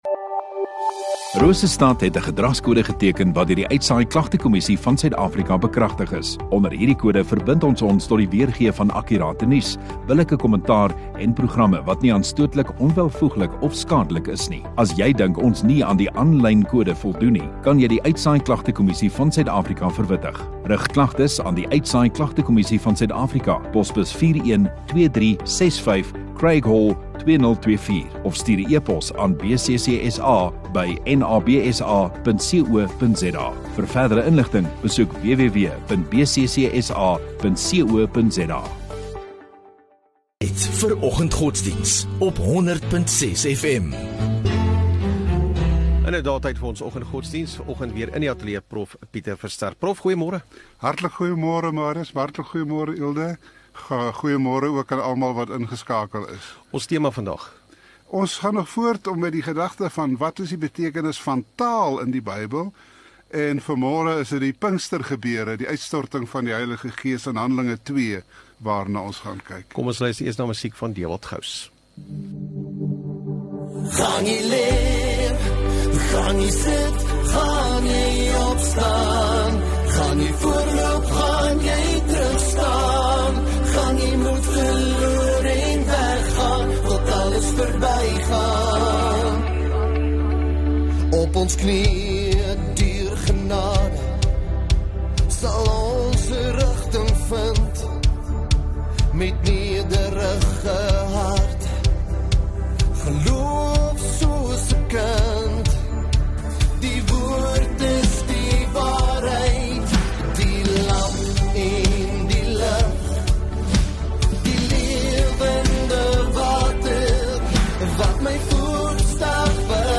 6 Aug Dinsdag Oggenddiens